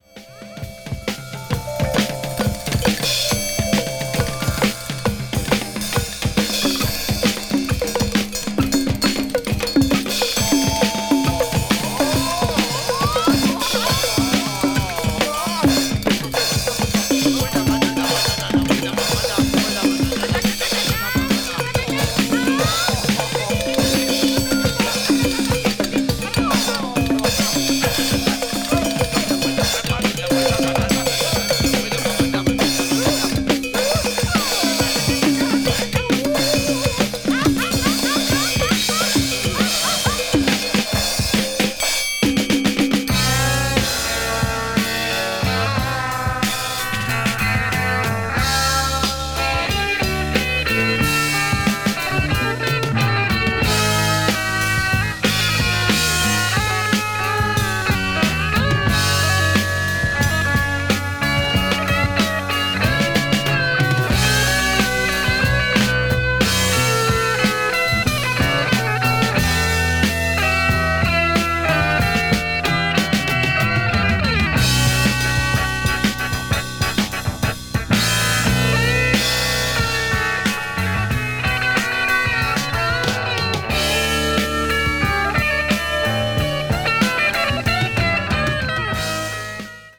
アシッディなロックからテープ・コラージュなどを駆使した実験的なサウンドまで
ユルユルなサイケデリック・ジャズ・ロック作品